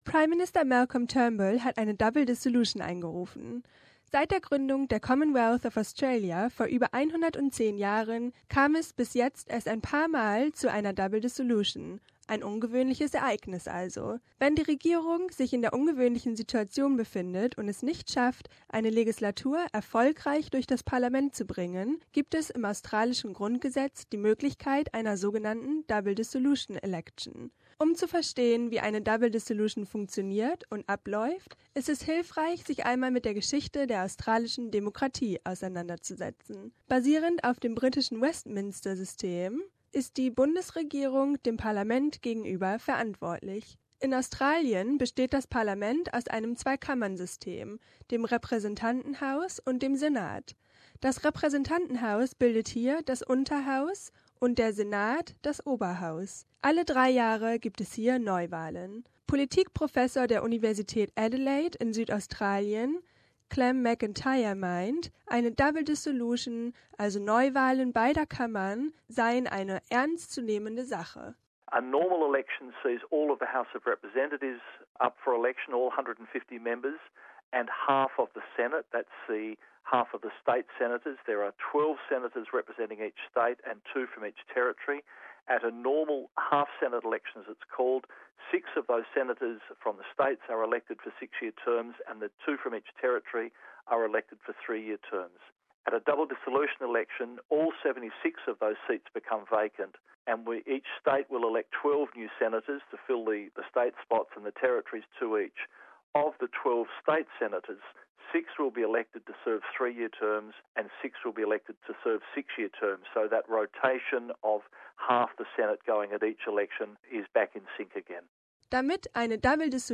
We explain the important political matter in an audio feature.